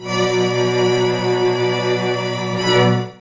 Index of /90_sSampleCDs/Zero-G - Total Drum Bass/Instruments - 2/track57 (Strings)